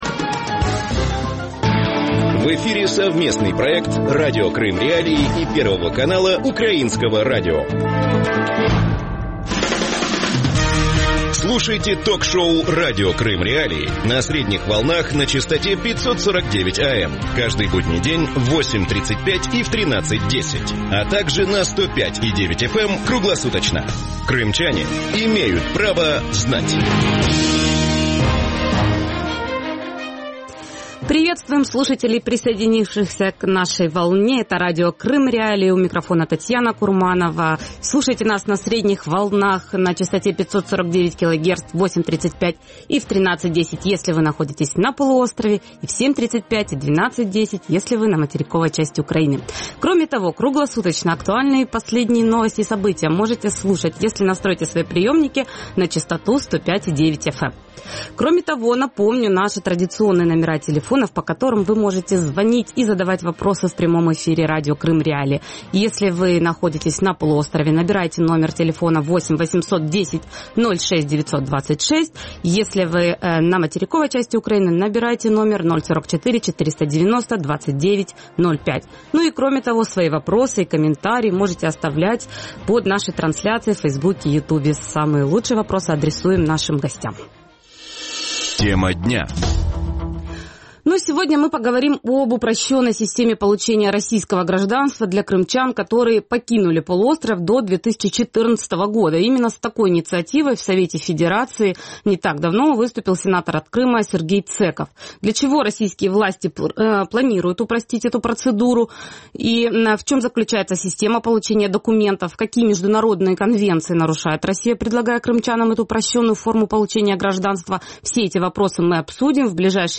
Радио Крым.Реалии в эфире 24 часа в сутки, 7 дней в неделю.